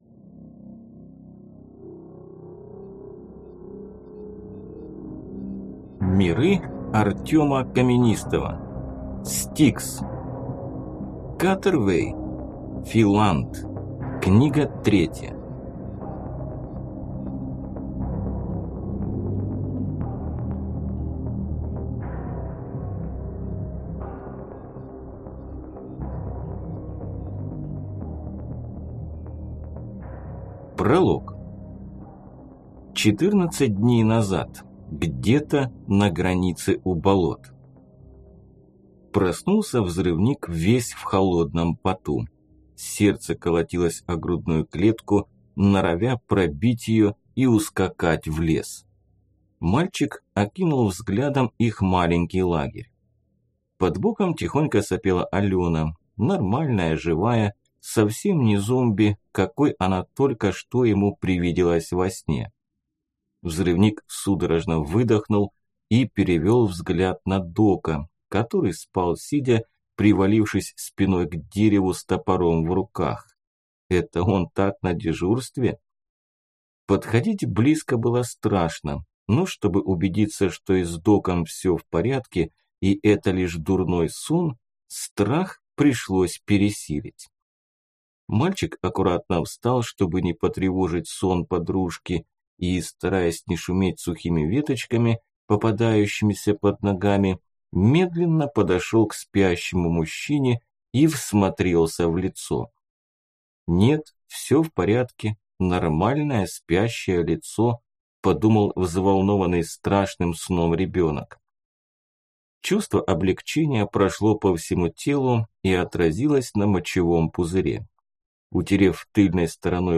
Аудиокнига S-T-I-K-S. Филант | Библиотека аудиокниг